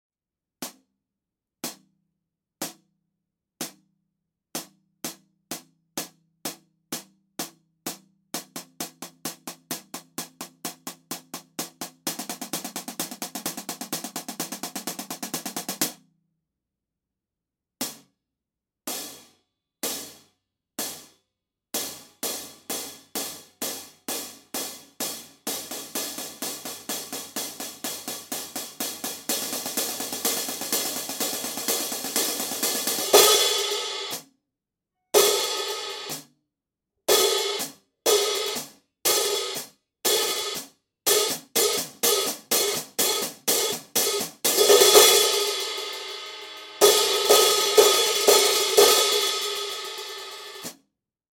New for 2018, the Armor Series Hi-hats provide great controlled sound with a nice resonance.
16″ Armor Hi-hat Cymbal: Apprix 2935 Grams Combined.